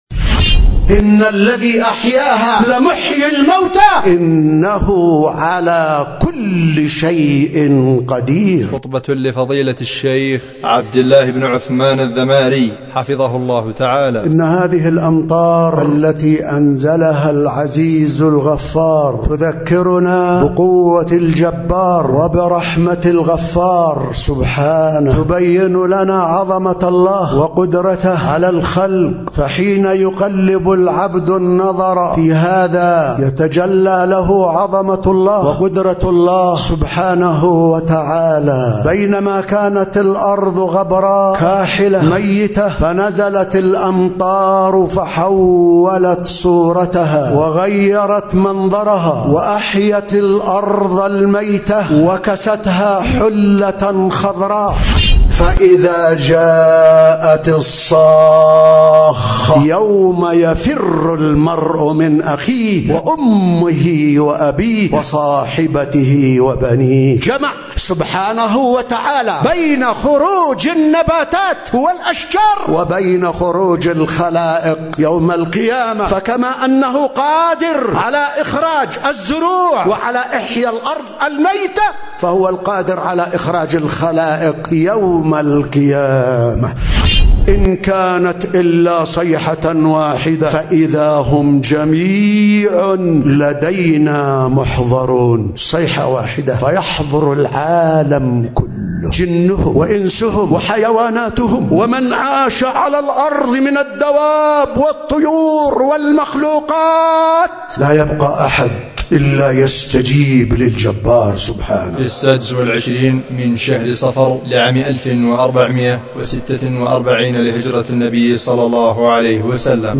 قالت لنا أحداث غزة🎙محاضرة